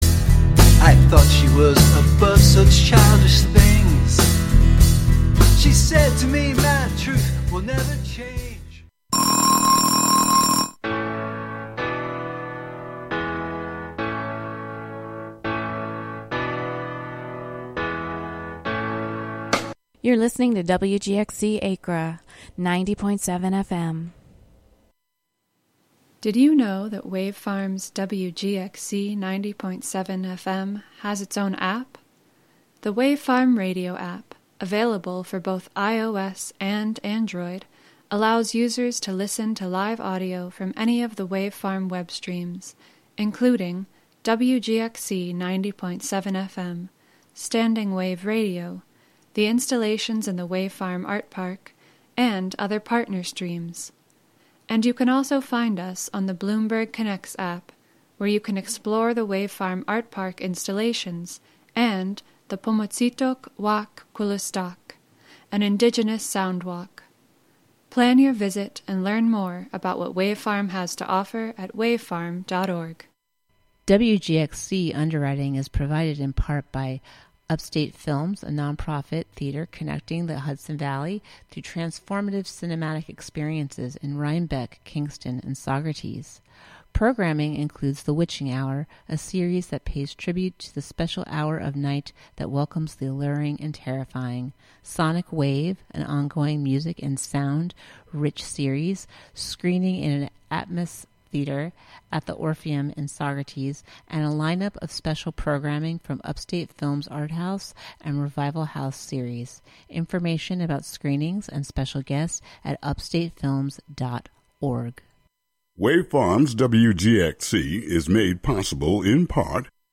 Each broadcast features candid conversations with both professional and aspiring artists, uncovering the heart of their creative process, the spark of their inspiration, and the journey that brought them into the art scene. From painters and sculptors to musicians and writers, we celebrate the richness of artistic expression in our community and beyond.